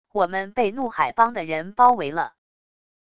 我们被怒海帮的人包围了wav音频生成系统WAV Audio Player